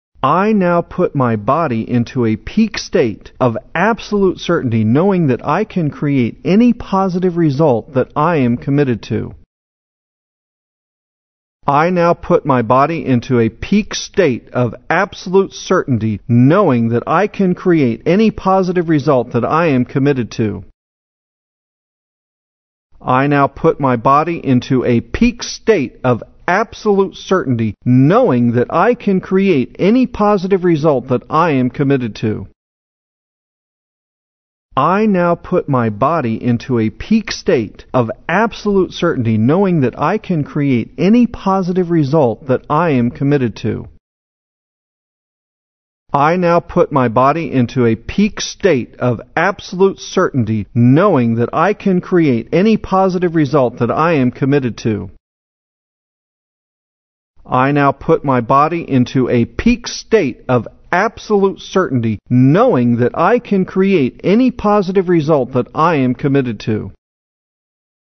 There are over 200 Power Affirmations—over 3 hours of professionally recorded audio at a Hollywood recording studio.